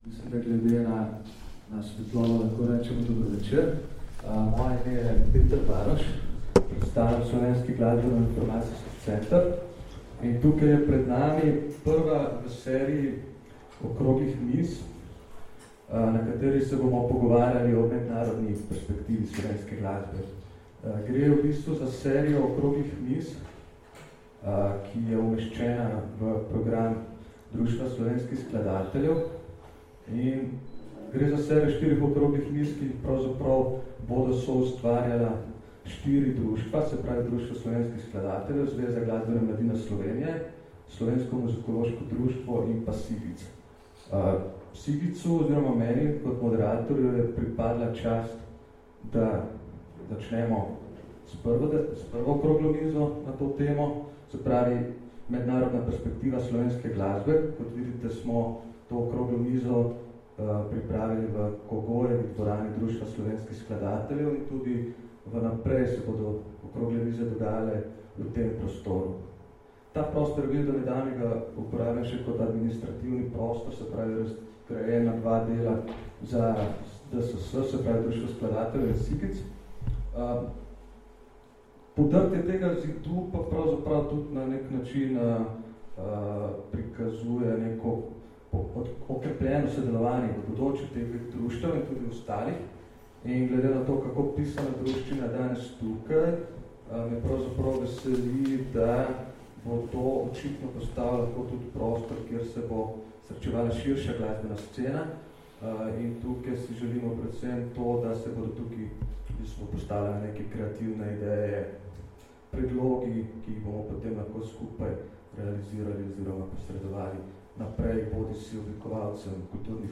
Skrajšani posnetek oziroma posnetek prve polovice okrogle mize o izkušnjah in priložnostih predstavljanja slovenske glasbe v mednarodnem kulturnem prostoru, ki se je odvila 12. novembra 2014 v Kogojevi dvorani Društva slovenskih skladateljev.
posnetek_okrogla_miza.mp3